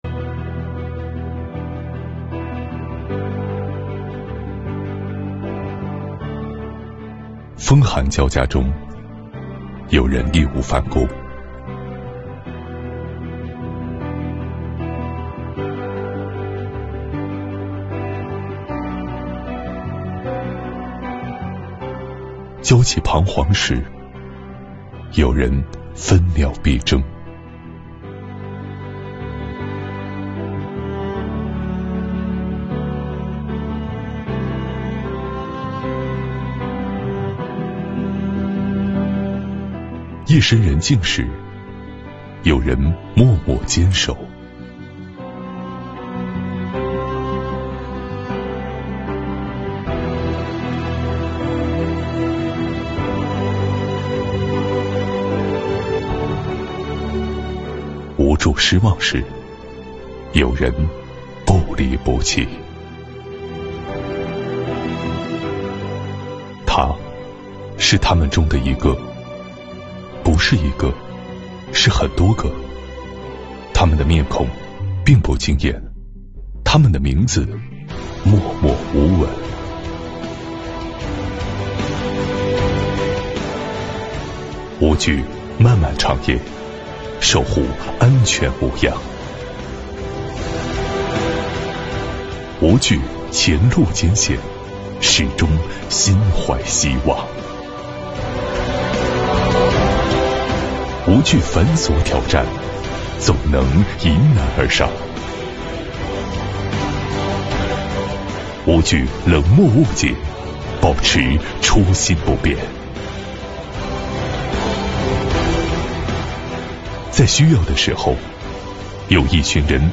- 主题诵读作品 -
该作品用八幅画面展示了铁路人面对客货上量、乡村振兴、恶劣天气等急难险重任务，党员干部职工关键时刻冲的上去，危难关头豁的出来的正面形象，以绘制沙画的形式与铿锵有力的朗诵相结合，创新朗诵载体，弘扬新时代铁路行业正能量。